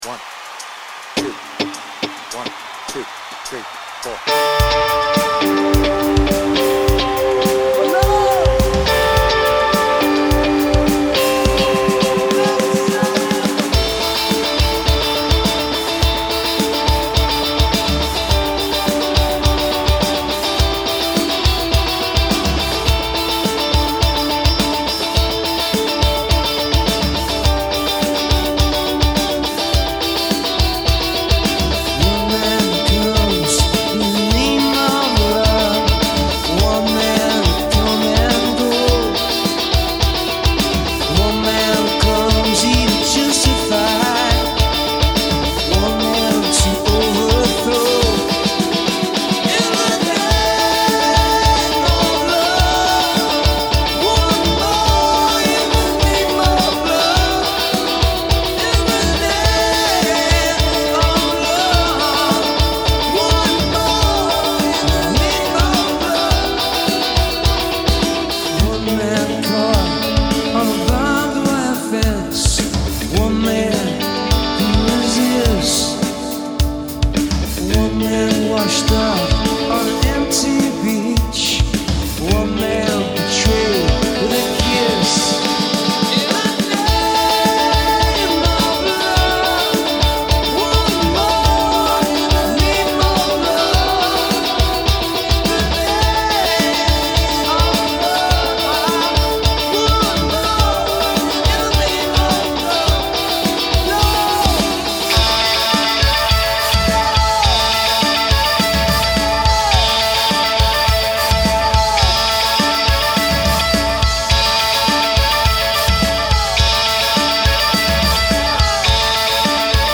BPM : 102
Tuning : Eb
With Vocals